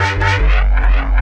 Bass 1 Shots (103).wav